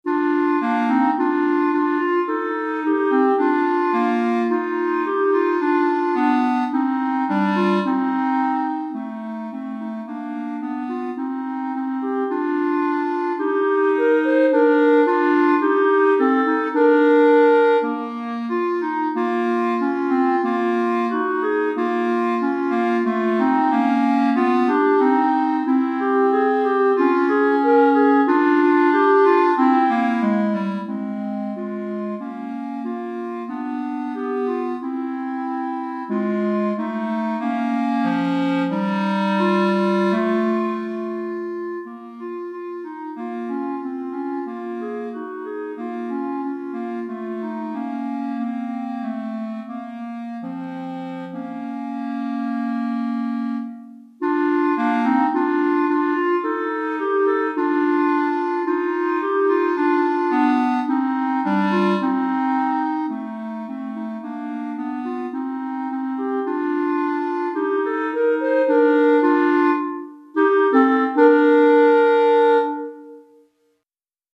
Pour 2 clarinettes DEGRE fin de cycle 1 Durée